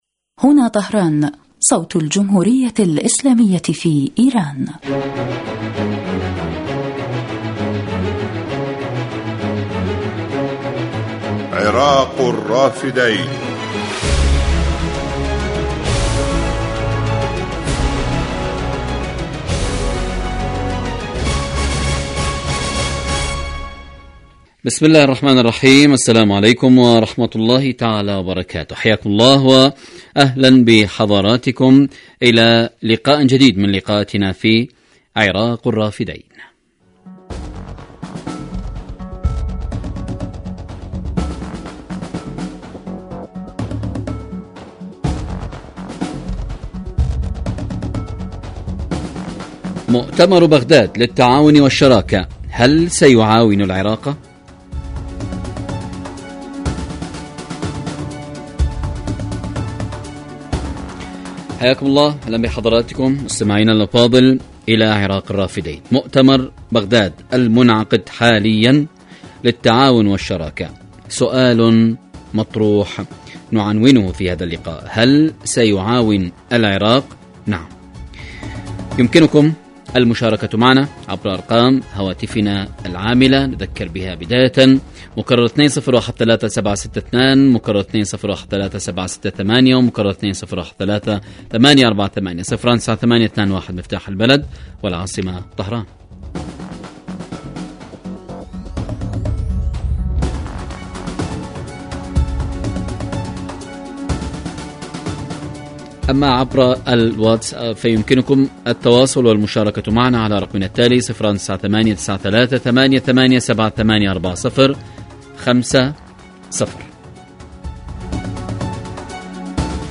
برنامج حي يتناول بالدراسة والتحليل آخرالتطورات والمستجدات على الساحة العراقية وتداعيات على الإقليم من خلال استضافة خبراء سياسيين ومداخلات للمستمعين عبر الهاتف
يبث هذا البرنامج على الهواء مباشرة أيام السبت وعلى مدى نصف ساعة